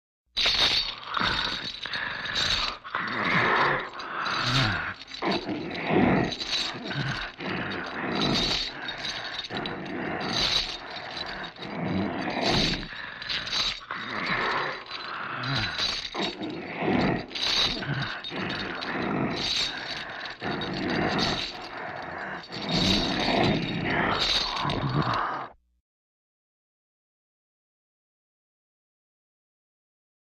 Звон цепей, тяжелое недовольное дыхание чудовища.
Idet_s_cepami_i_rychit.mp3